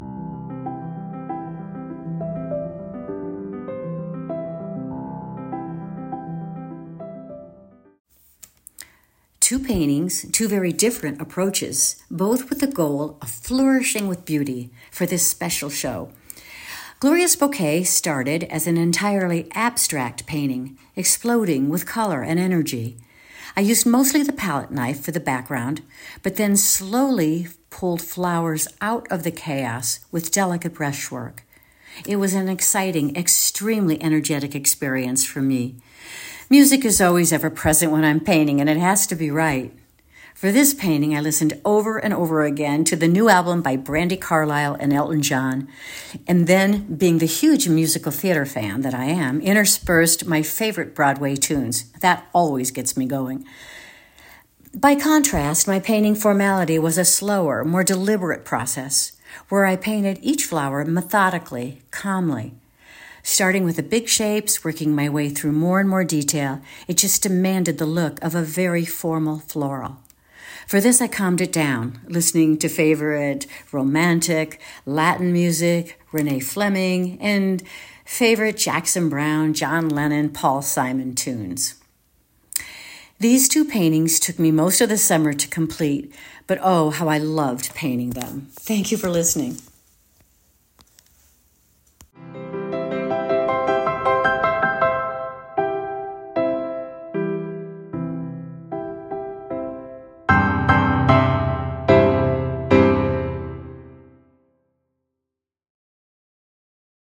Discover short audio reflections from the artists for Flourishing with Beauty.